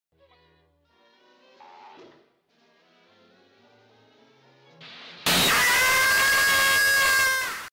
Sound Buttons: Sound Buttons View : TOM SCREAM EARRAPE
tom-scream-earrape.mp3